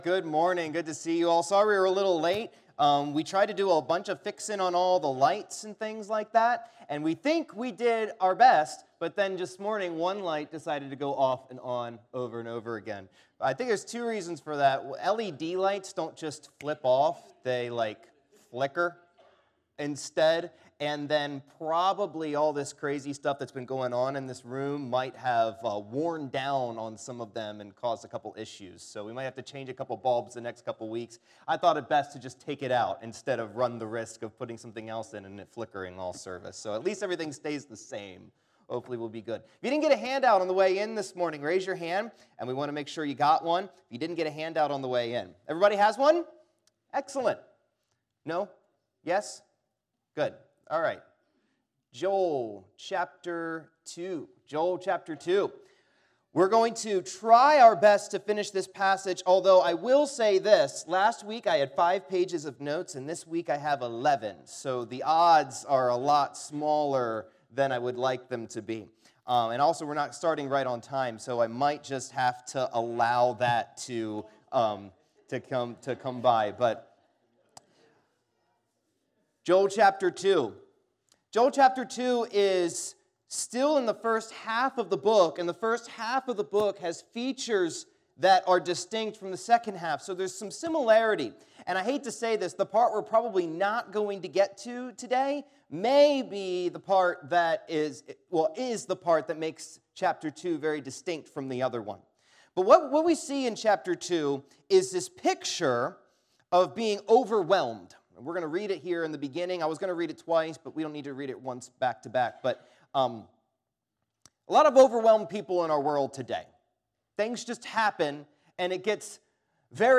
Service Type: Auditorium Bible Class